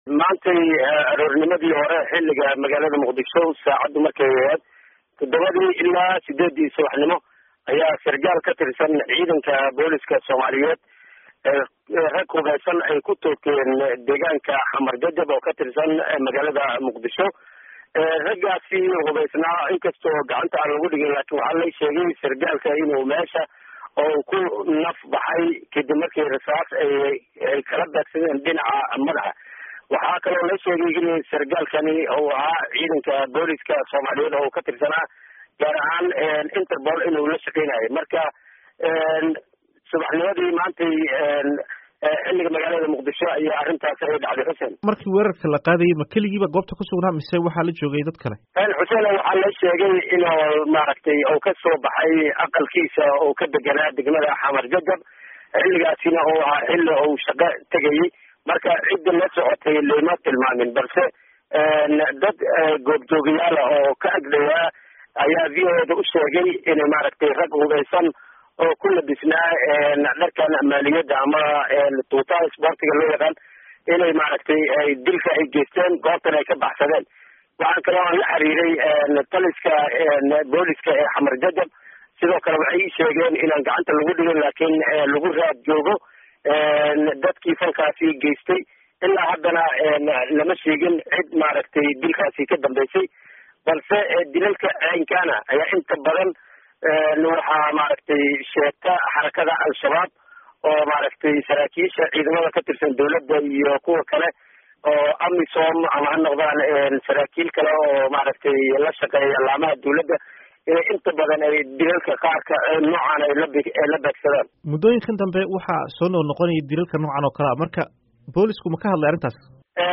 Dhegayso waraysi ku saabsan dilka Sarkaalka ee Muqdisho